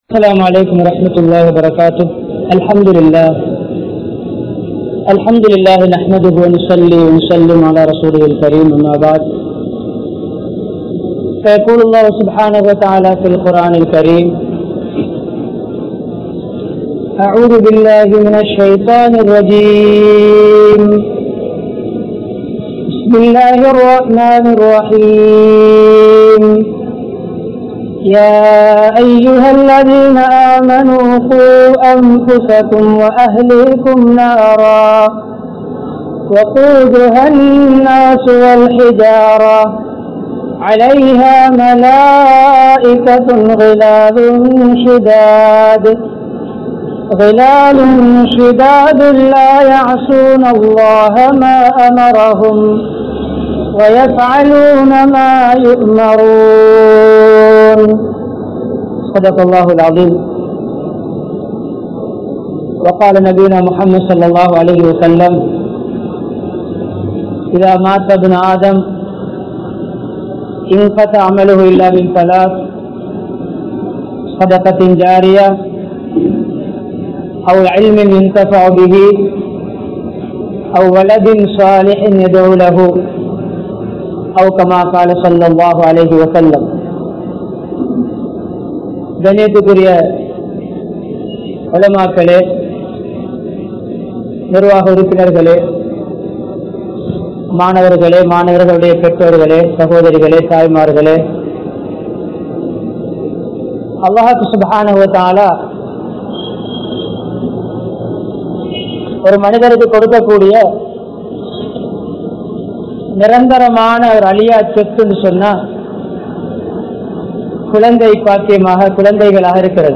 Pillaihal Seeraliya Petroarahal Kaaranama? (பிள்ளைகள் சீரழிய பெற்றோர்கள் காரணமா??) | Audio Bayans | All Ceylon Muslim Youth Community | Addalaichenai
Wellampittiya, Sedhawatte, Ar Rahmath Jumua Masjidh